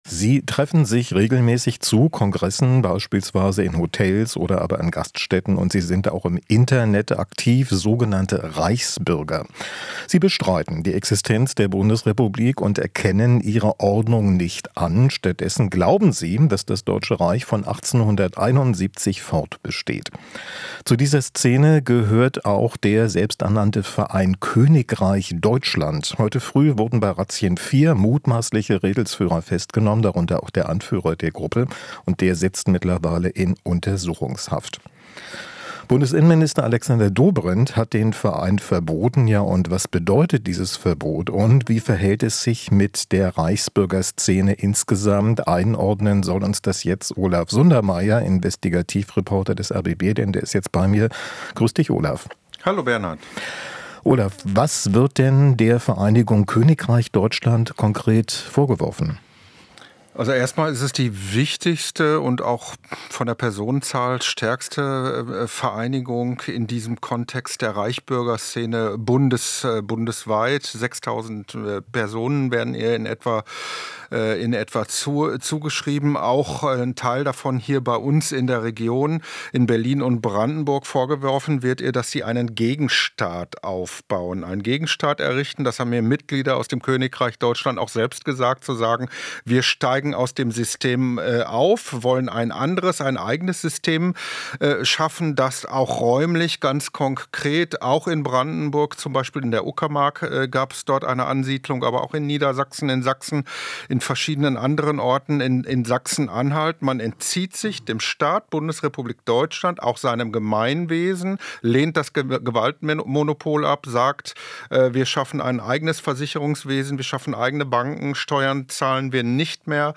Interview - Terrorexperte: "Königreich Deutschland"-Verbot längst überfällig